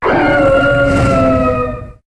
Cri de Reshiram dans Pokémon HOME.